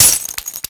RobosaHit.ogg